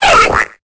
Cri de Canarticho dans Pokémon Épée et Bouclier.